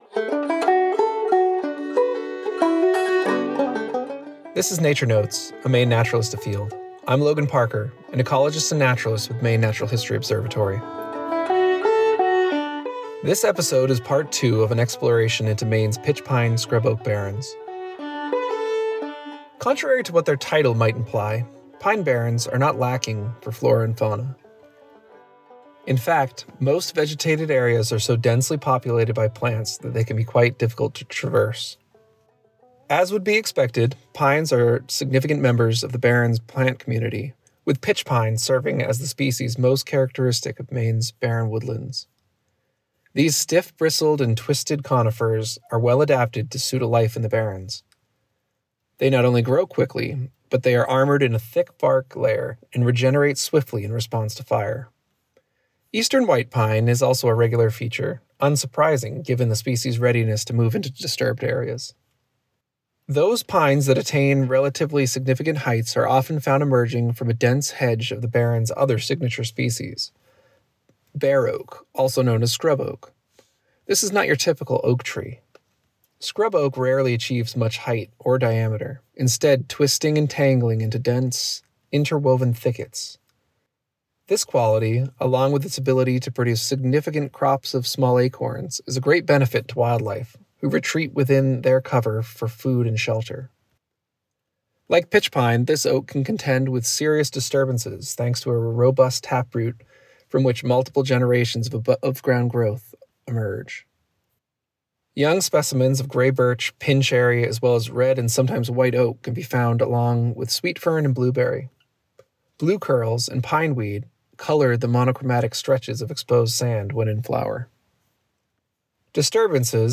MP3 Audio Archive Recordings (aka Podcasts) of all locally produced spoken word programming from Community Radio WERU 89.9 FM Blue Hill, Maine - Part 29